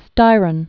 (stīrən), William Clark 1925-2006.